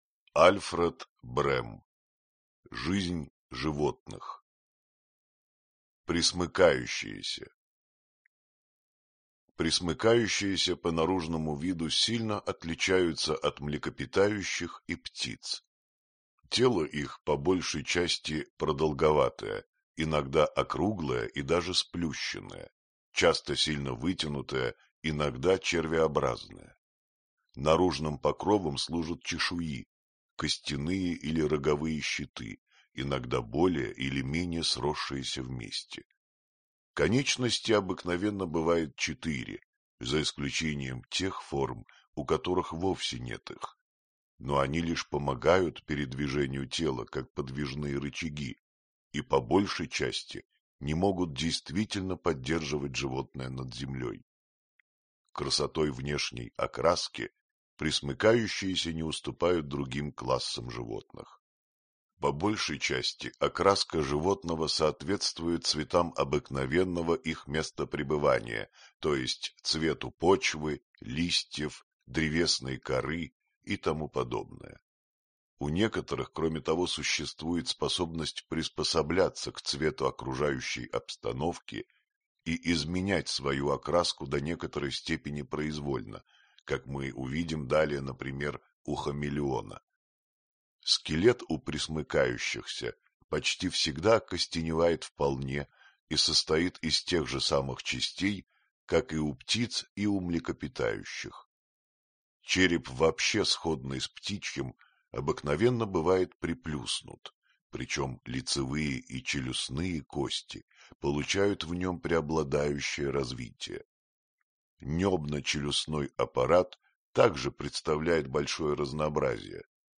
Аудиокнига Жизнь животных. Пресмыкающиеся | Библиотека аудиокниг